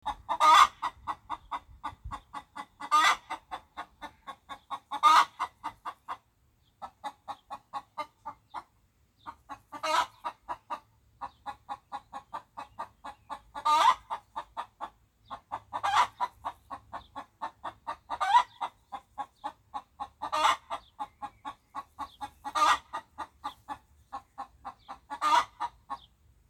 Chicken Noises